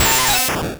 Cri d'Aspicot dans Pokémon Rouge et Bleu.